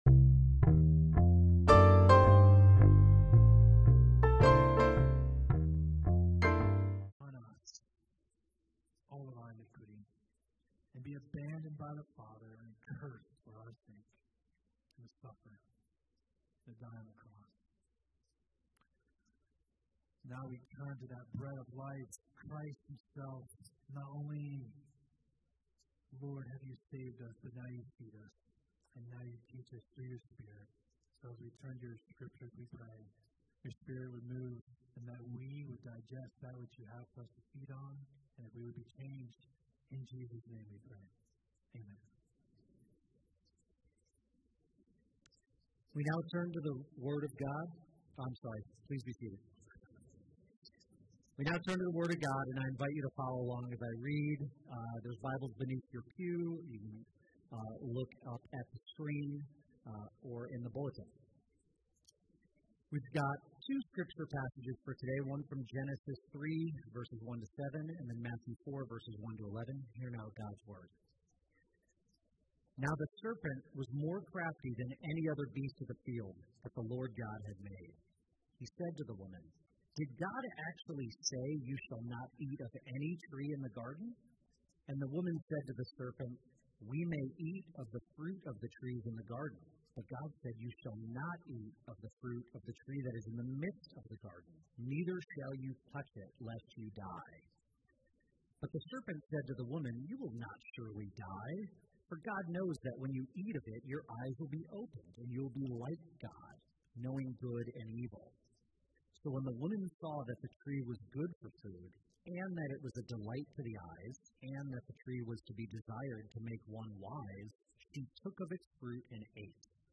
Passage: Genesis 3:1-7, Matthew 4:1-11 Service Type: Sunday Worship